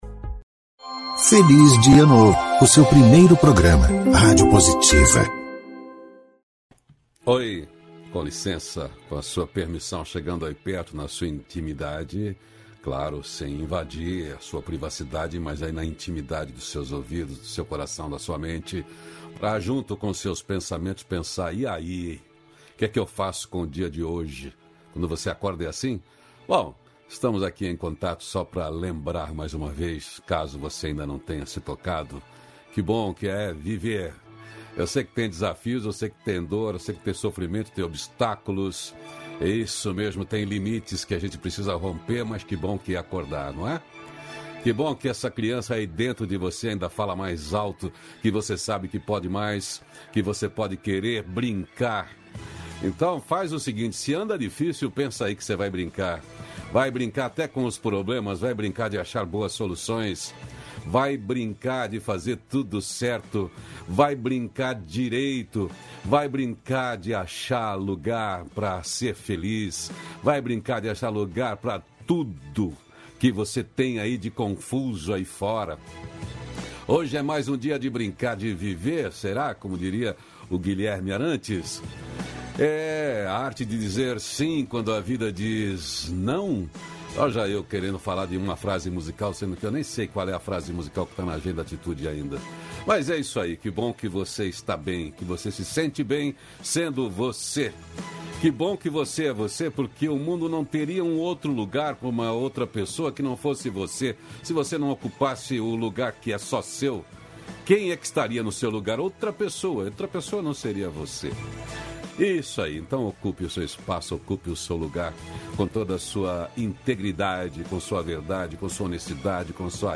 -542FelizDiaNovo-Entrevista.mp3